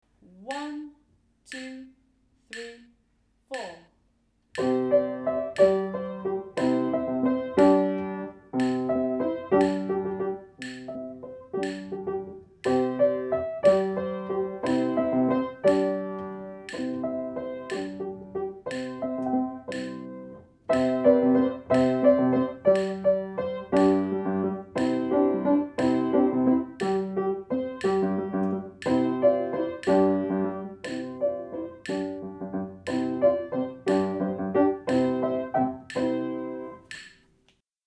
Landler Piano Part mp3
Landler_piano.mp3